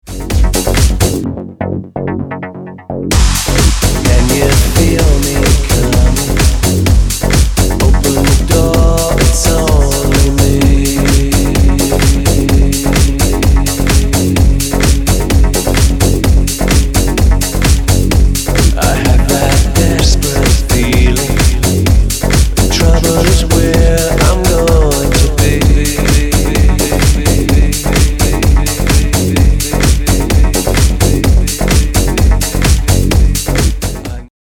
Alternative Vocal